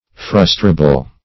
Search Result for " frustrable" : The Collaborative International Dictionary of English v.0.48: Frustrable \Frus"tra*ble\, a. [L. frustrabilis: cf. F. frustable.]